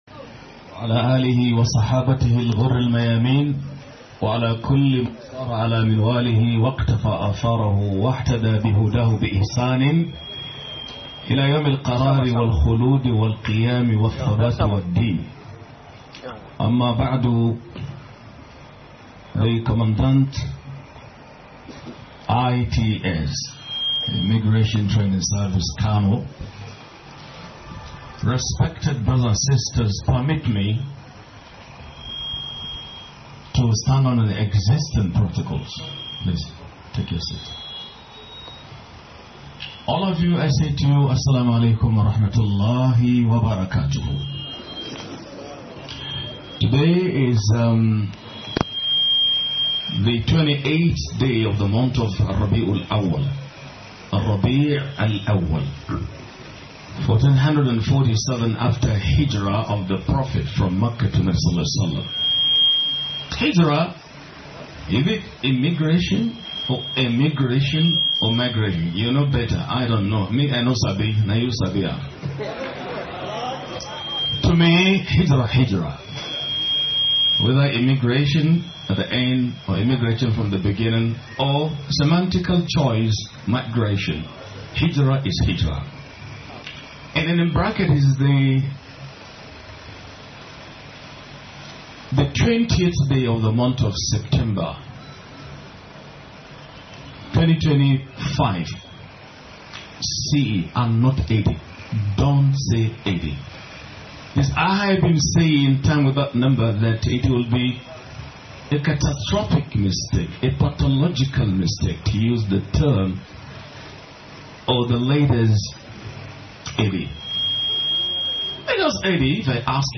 UPRIGHTNESS AND INTEGRITY IN NIGERIA IMMIGRATIONS SERVICE A TRUST FROM ALLAH - MUHADARA